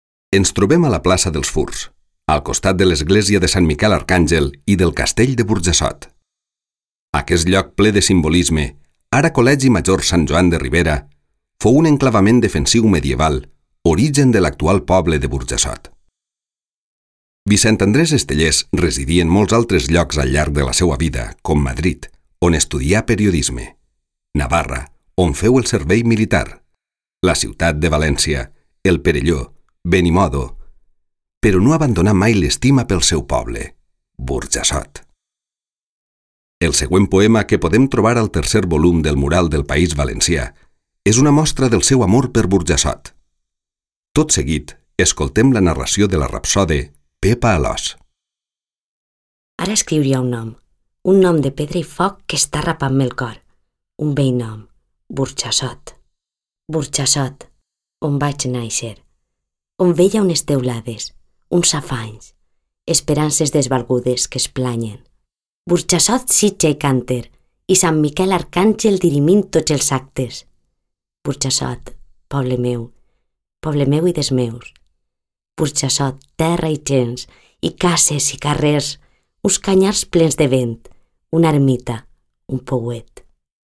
Explicació en àudio: